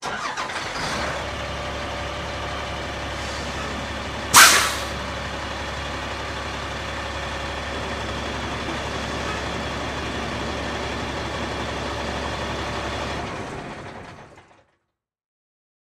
tr_sbus_startidleoff_01_hpx
Exterior and interior points of view of school bus starts and stalls, and air brake releases. Vehicles, School Bus Bus, School Engine, Motor